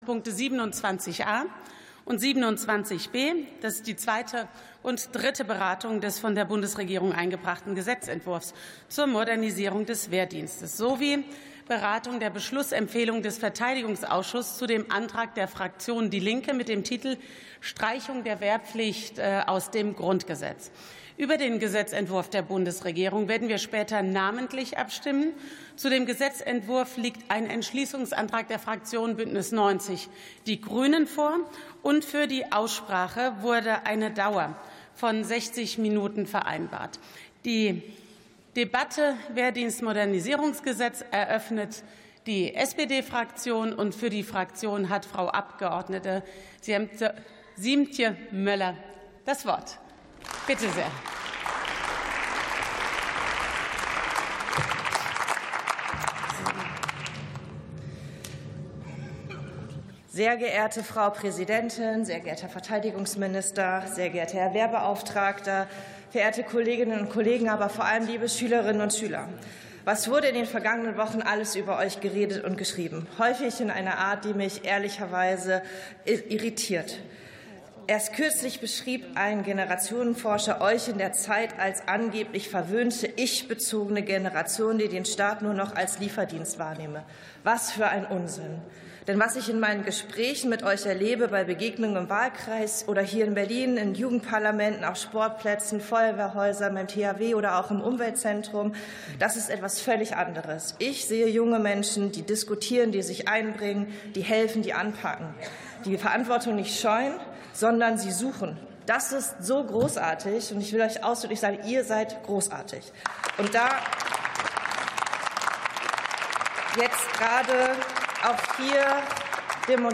Sitzung vom 05.12.2025. TOP 27: Wehrdienst-Modernisierungsgesetz ~ Plenarsitzungen - Audio Podcasts Podcast